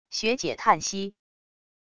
学姐叹息wav音频